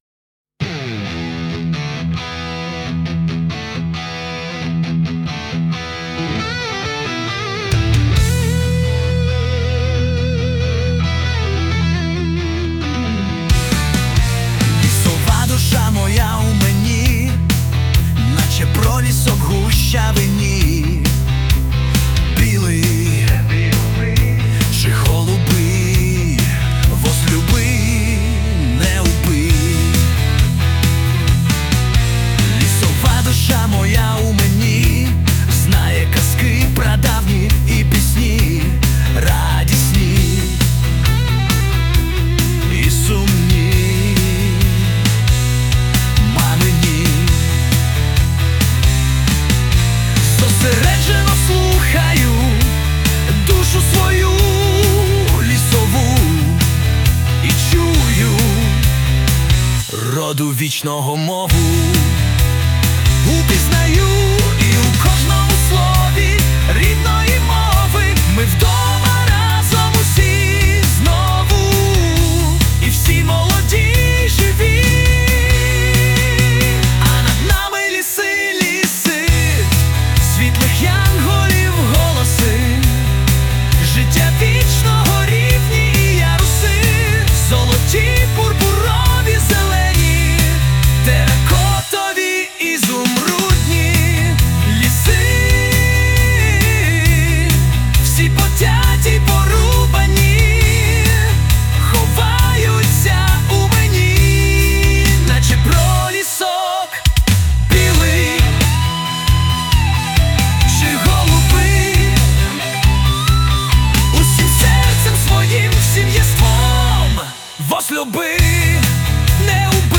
музику і виконання згенерував ШІ